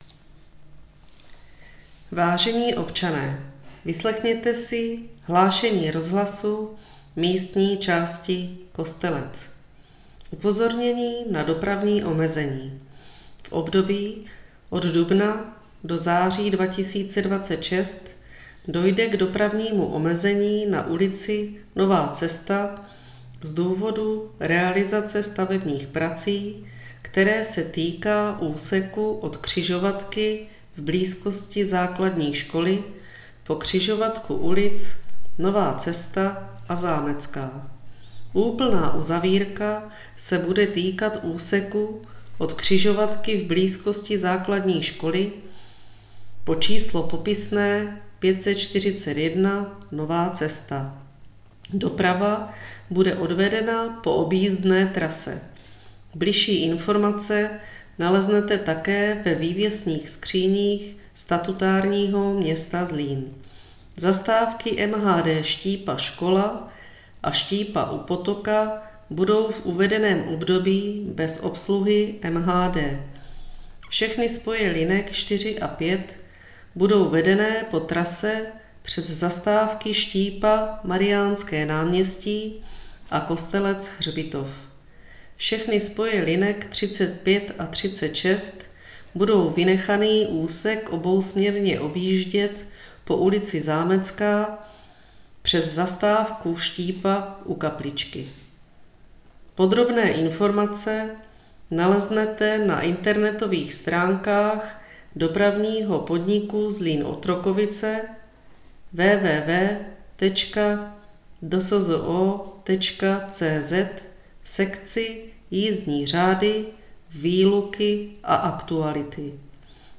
Hlášení místního rozhlasu
Samotné hlášení provádí pracovníci kanceláří místních částí ze svých pracovišť.